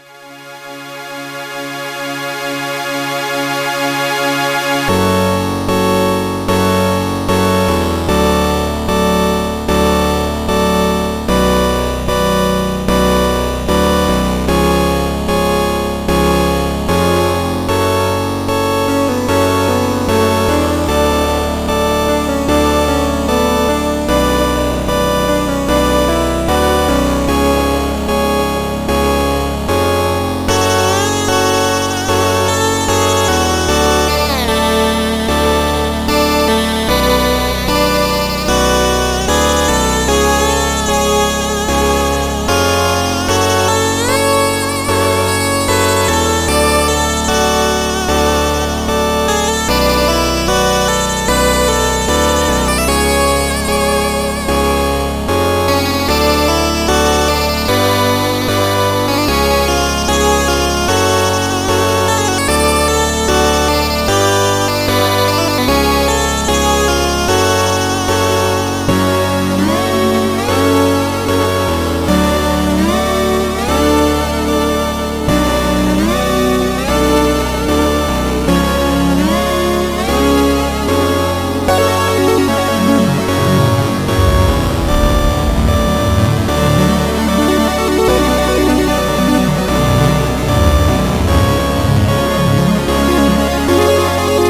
An Amiga chiptune, reflecting a sad story.